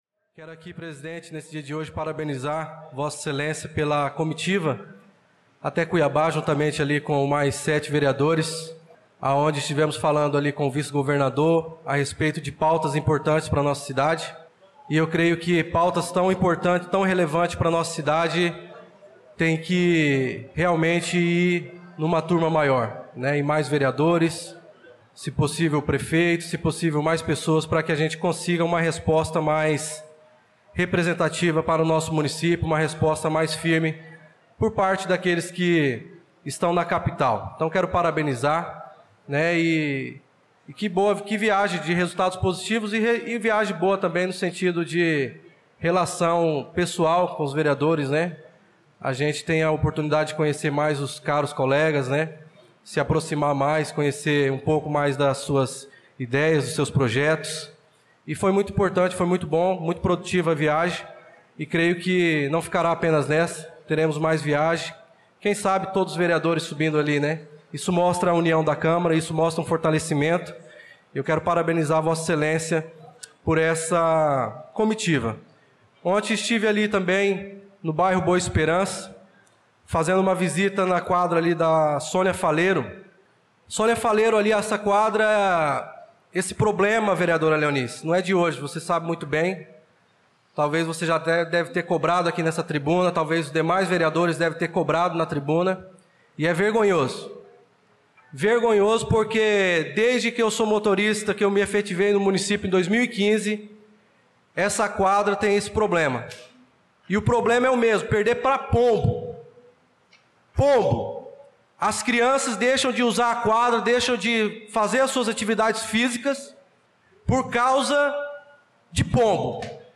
Pronunciamento do vereador Darlan Carvalho na Sessão Ordinária do dia 18/02/2025